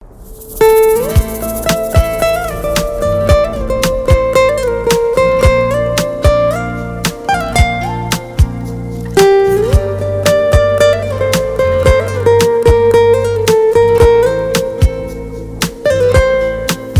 ژانر: بی کلام
زنگ خور غمگین بی کلام عاشقانه